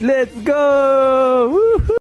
LETS GOOOOOO OUH: botón de efectos de sonido instantáneos | Myinstants